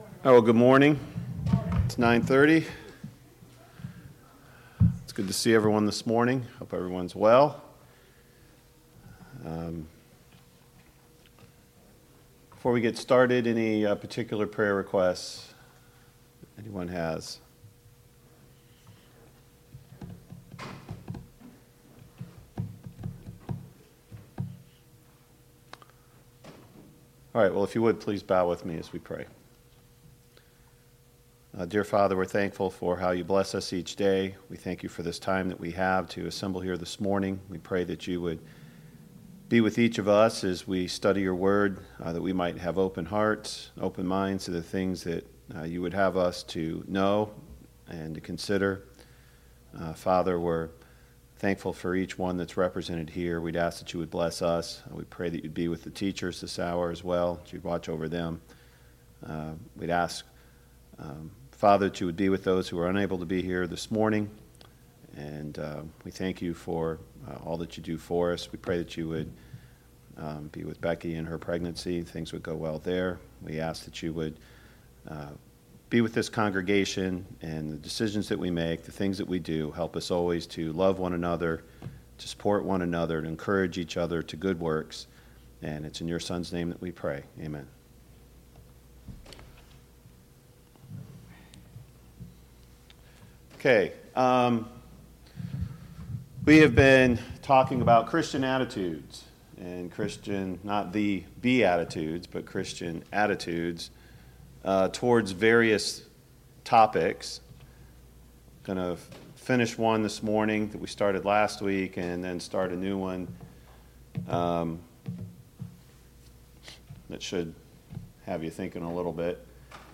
A Study of the Christian Attitude Service Type: Sunday Morning Bible Class « 4.